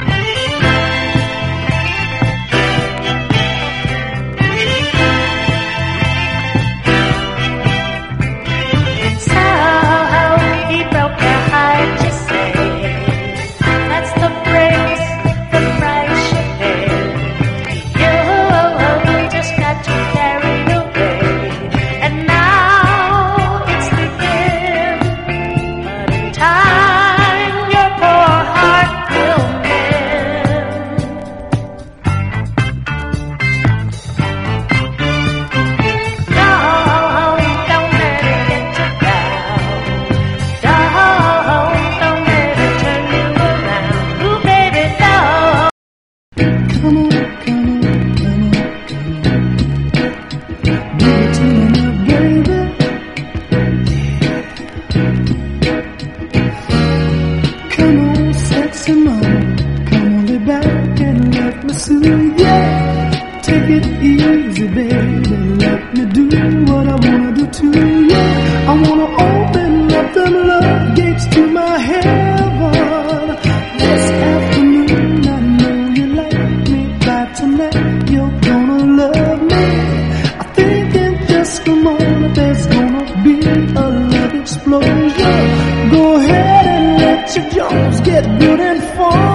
60'Sポップ/ロック/ソウルのマイナー・カヴァーを多数収録！